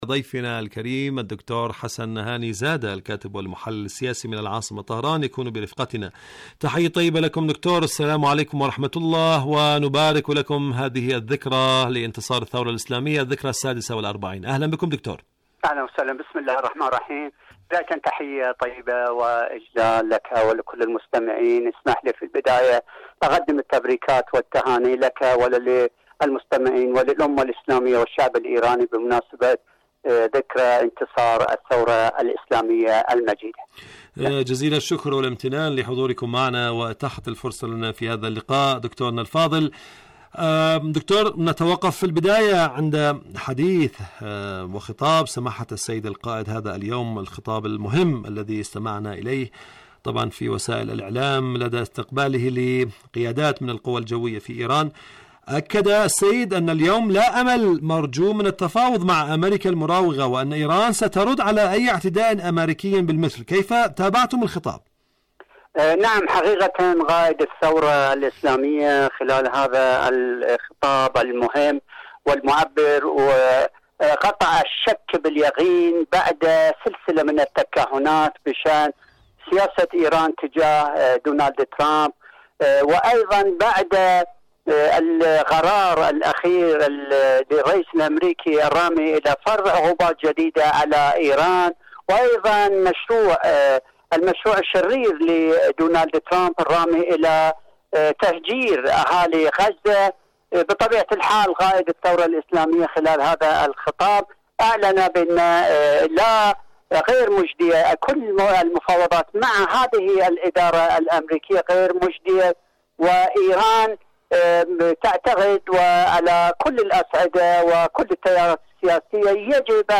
برنامج حدث وحوار مقابلات إذاعية